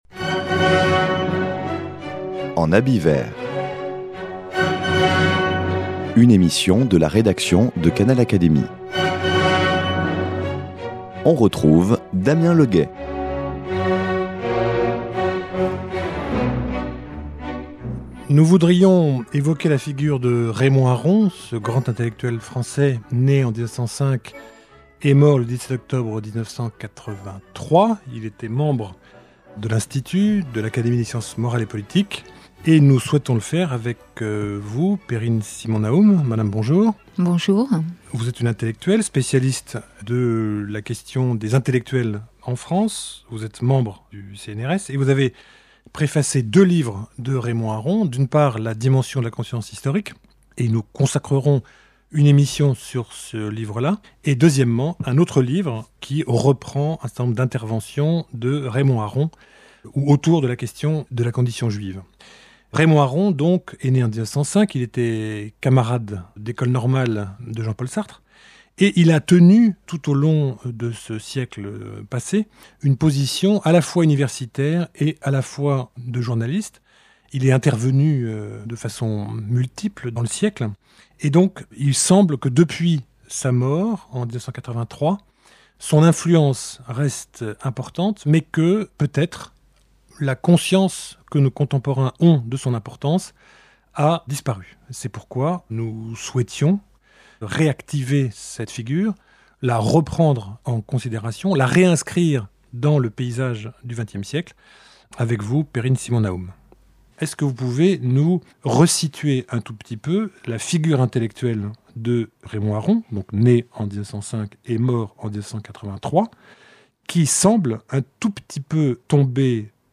Voici un premier entretien